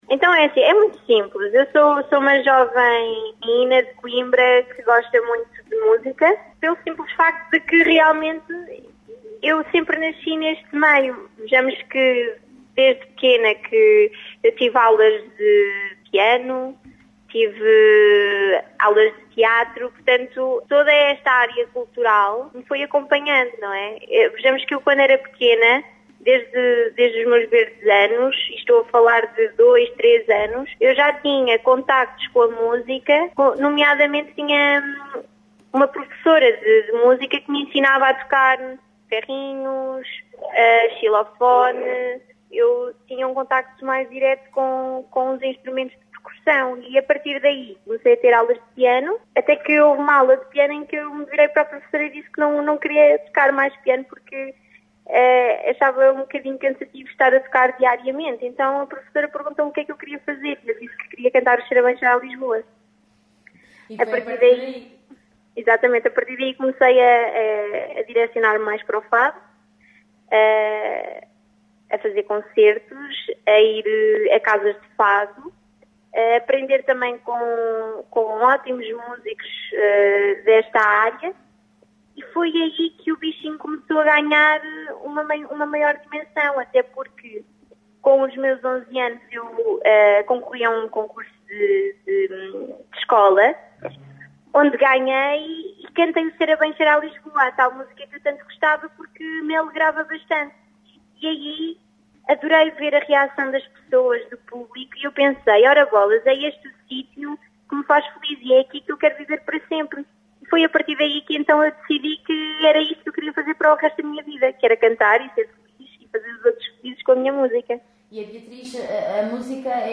entrevista-fadista.mp3